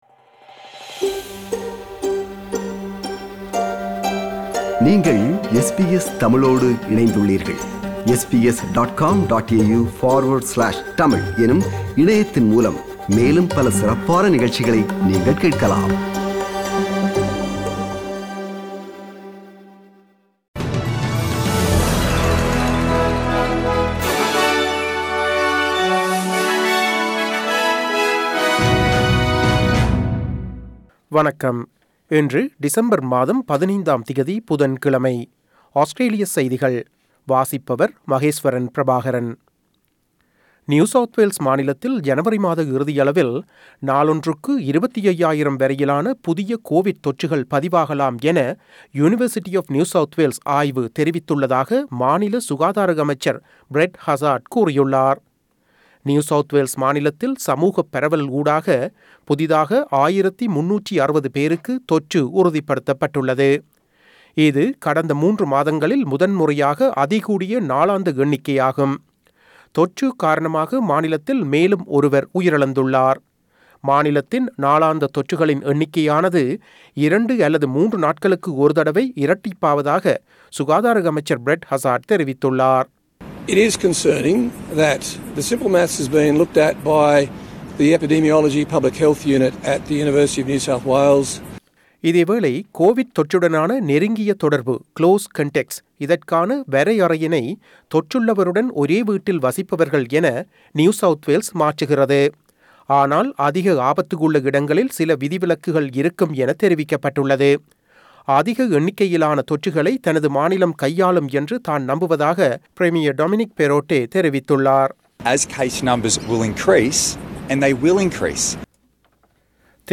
Australian news bulletin for Wednesday 15 December 2021.